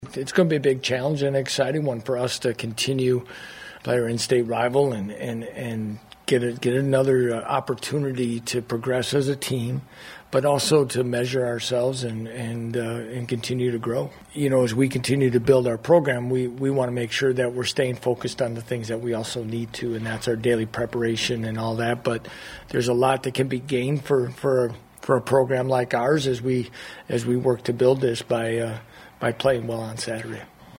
Kansas Coach Lance Leipold says this is another opportunity to get better.